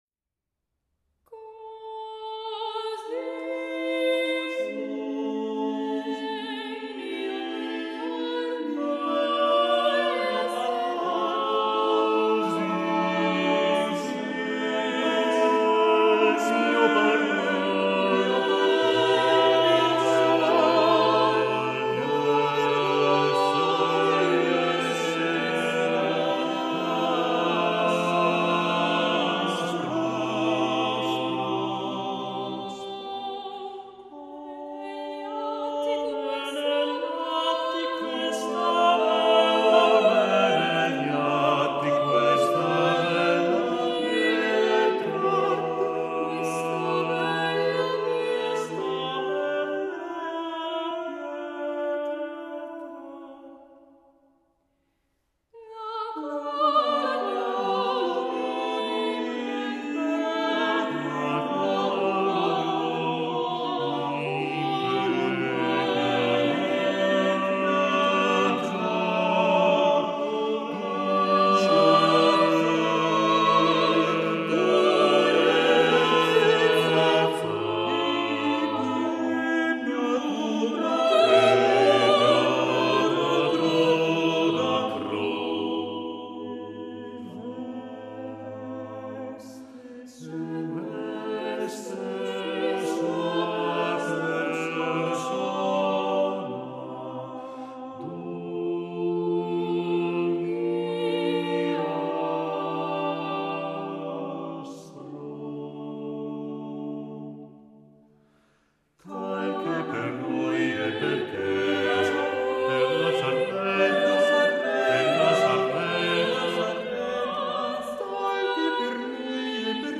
Marenzio, Così nel mio parlar (madrigale).mp3